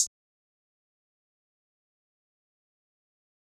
Hi Hat (AMG).wav